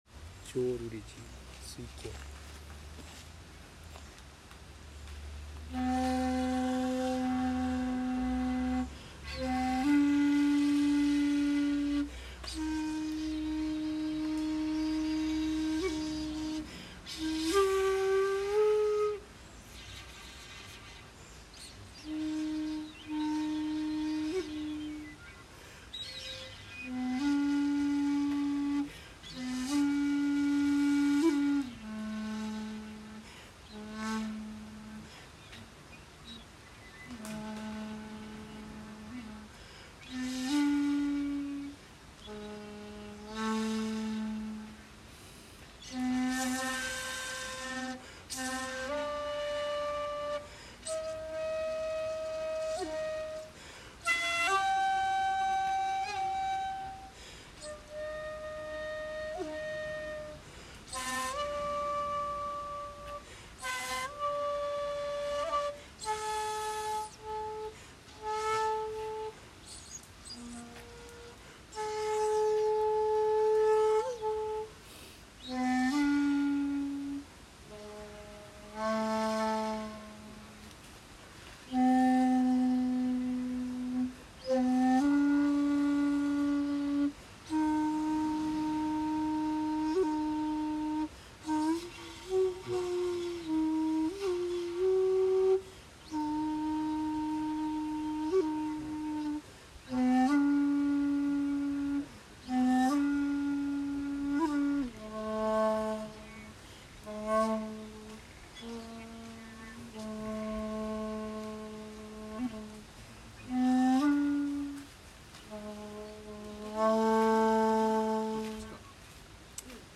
さて今日の打ち止めに納経して、尺八を吹奏しました。
◆　（尺八音源：浄瑠璃寺にて「水鏡」）
524-医王山浄瑠璃寺.mp3